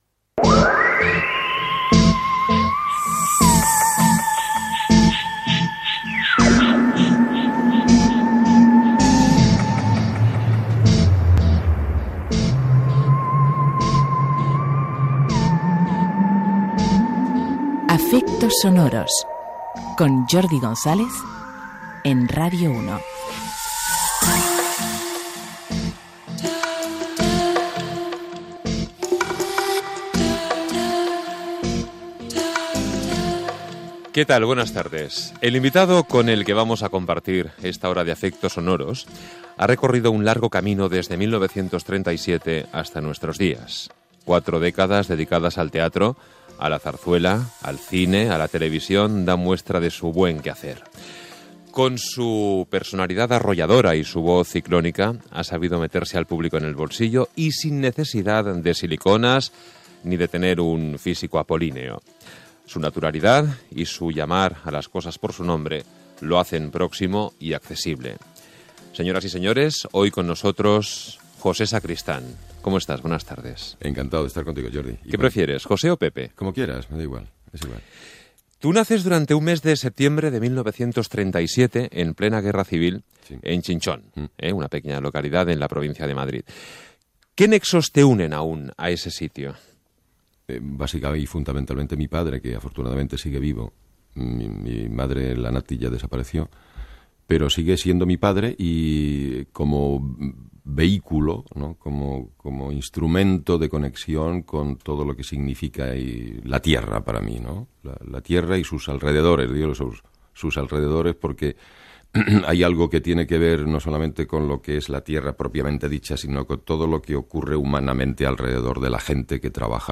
presentació i entrevista a l'actor José Sacristán sobre la seva trajectòria personal i professional Gènere radiofònic Entreteniment